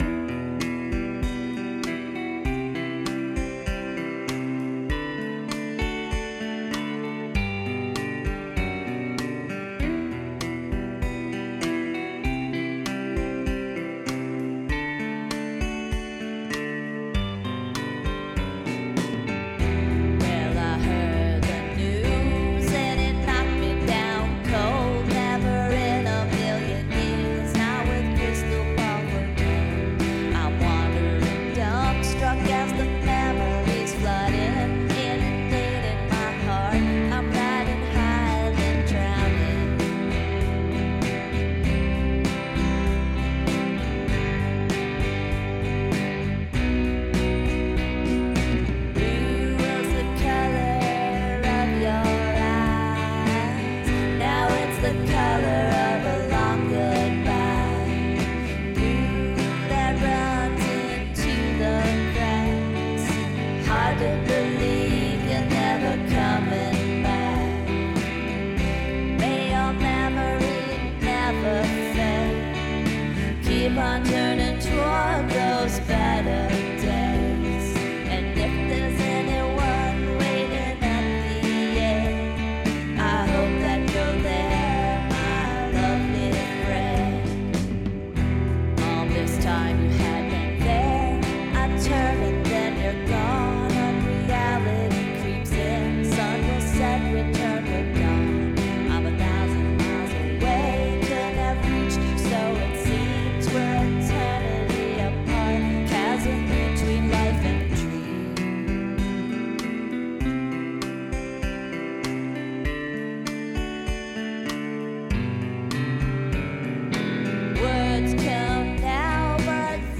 Gorgeous and appropriately understated.